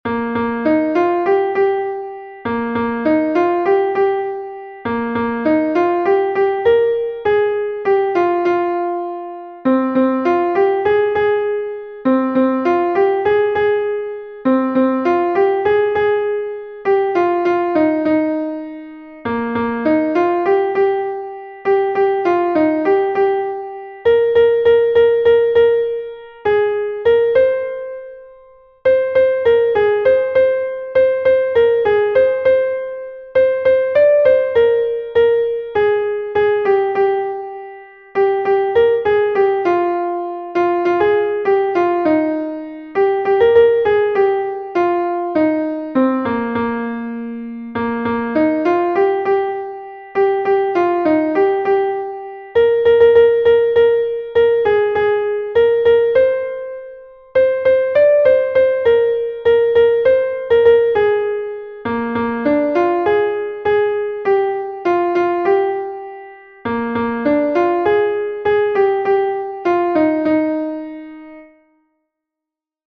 A-B-A1-C-D-A1-E